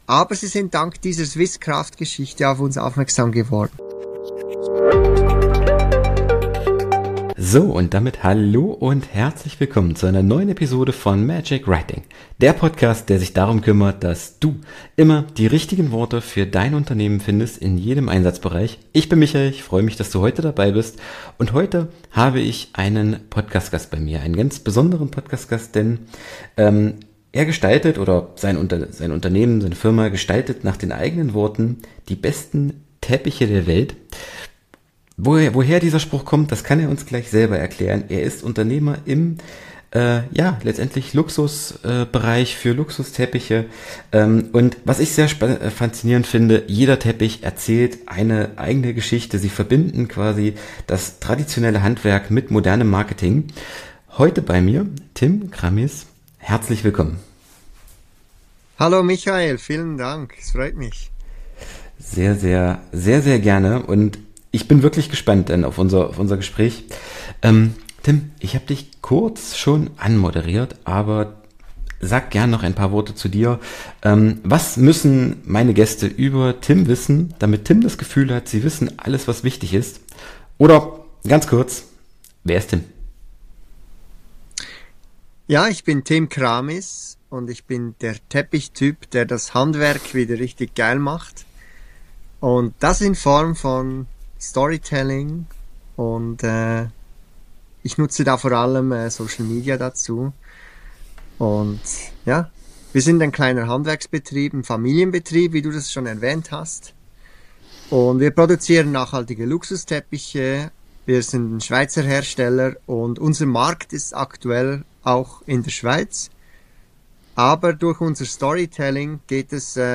Folge 298: Durch Storytelling zu mehr Sichtbarkeit im Handwerk - Interview